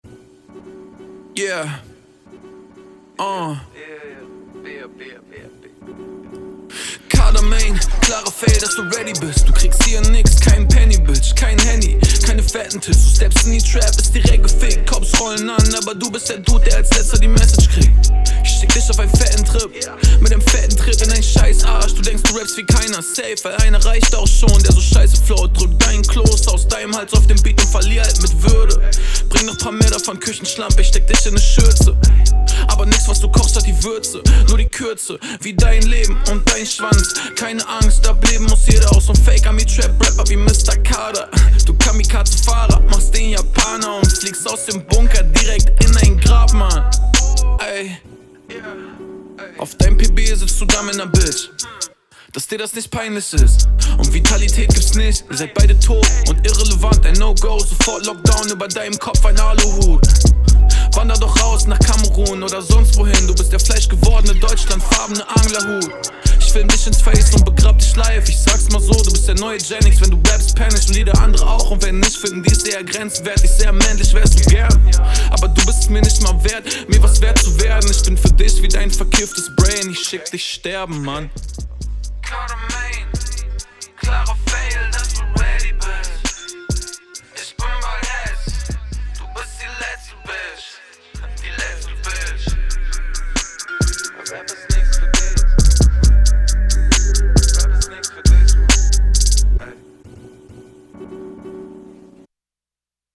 Stimmeneinsatz ist sehr dope, Flowst richtig dope - kommst sau gut auf dem Beat!
Der Beat is so dope.